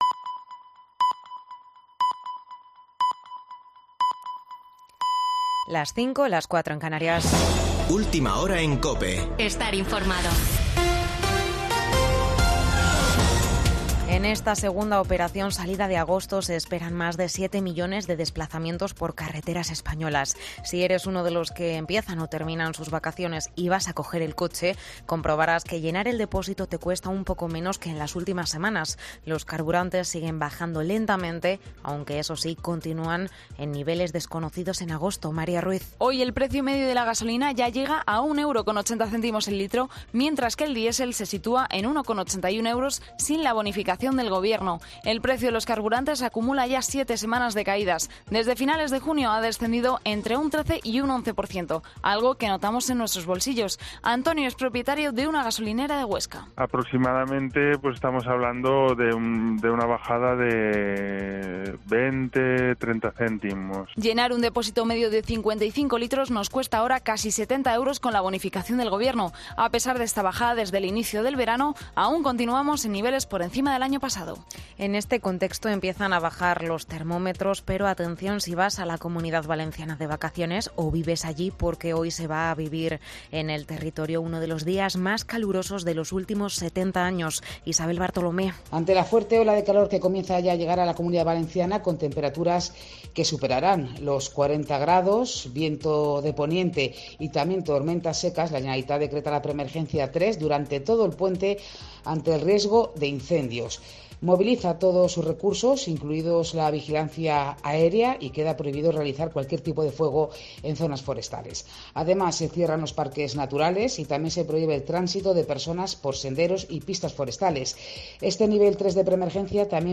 Boletín de noticias de COPE del 13 de agosto de 2022 a las 05.00 horas